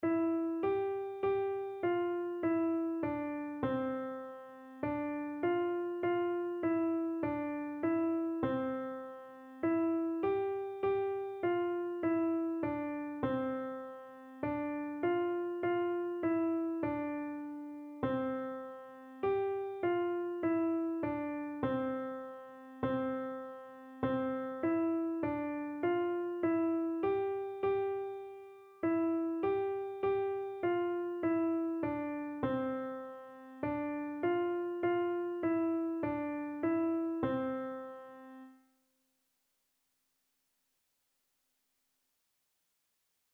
4/4 (View more 4/4 Music)
Piano  (View more Beginners Piano Music)
Classical (View more Classical Piano Music)